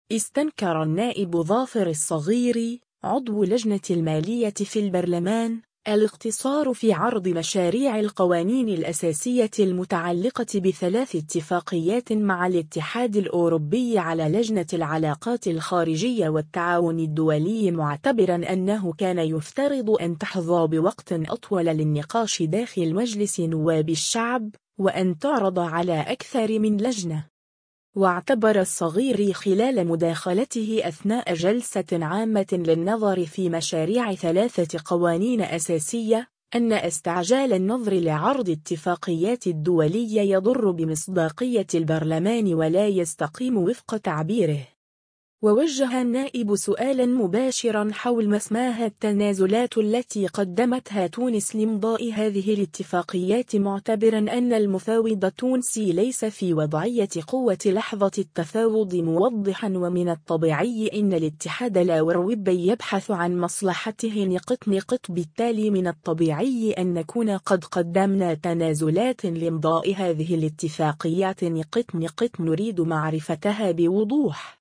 واعتبر الصغيري خلال مداخلته أثناء جلسة عامة للنظر في مشاريع 3 قوانين أساسية،أن إستعجال النظر لعرض اتفاقيات الدولية يضر بمصداقية البرلمان ولا يستقيم وفق تعبيره.